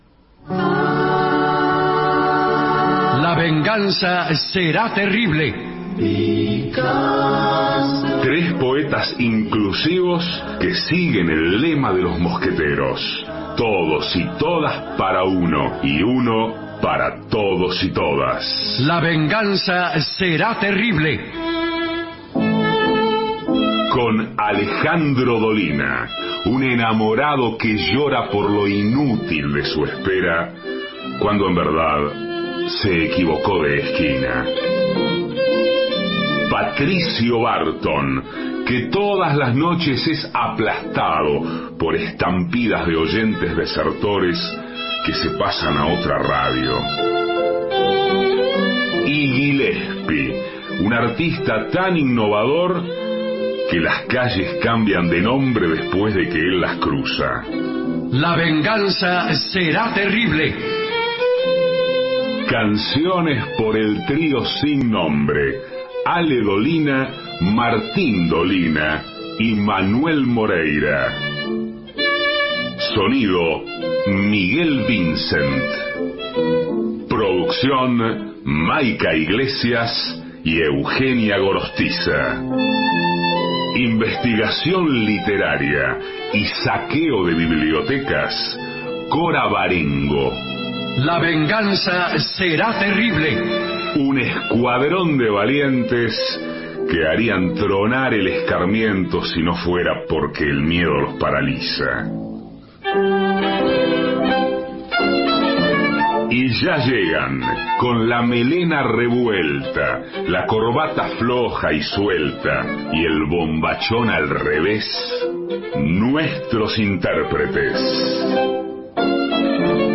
Estudios Radio AM 750 Alejandro Dolina